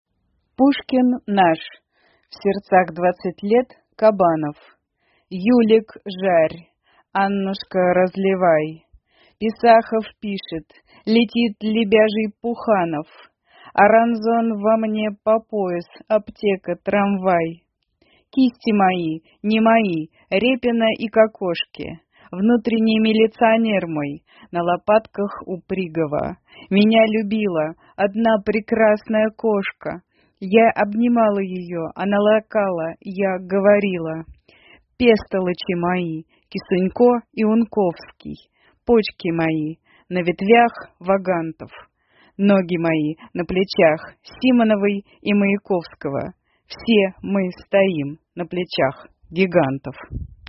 читает стихотворение «пушкин наш…»